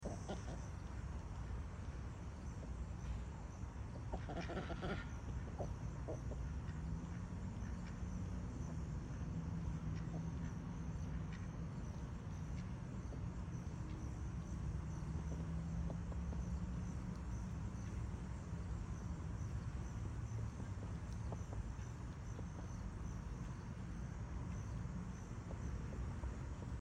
Just Some Morning Duck Talk Sound Effects Free Download